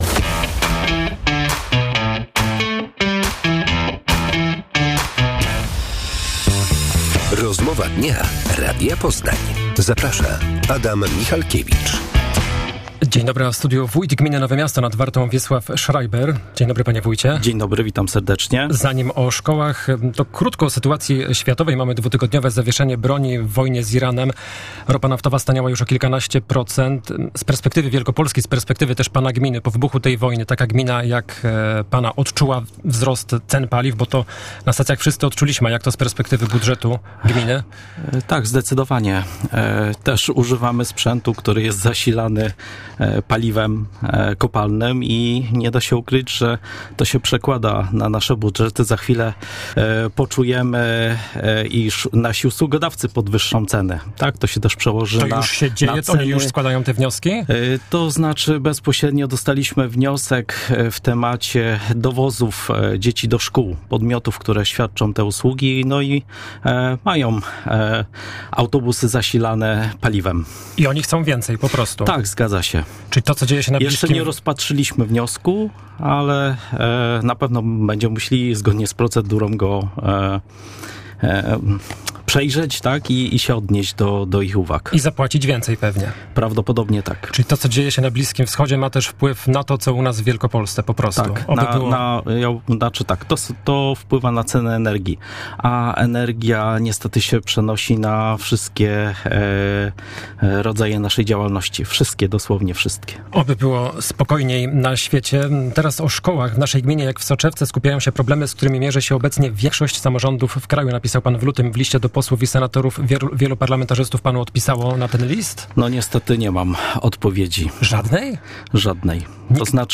Wielkopolscy parlamentarzyści zignorowali apel wójta, który prosił o pomoc w sprawie utrzymania szkół. W porannej Rozmowie Dnia Radia Poznań wójt gminy Nowe Miasto nad Wartą w powiecie średzkim Wiesław Schreiber przyznał dziś, że na wysłany w lutym list nie odpisał mu żaden poseł lub senator.